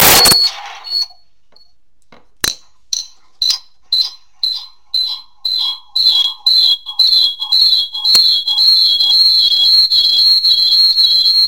2 cellulari, speaker bluetooth, martello.
Performance, 2020.
producono l’effetto Larsen.
amplifica l’effetto Larsen.
ad ogni ripetizione in loop.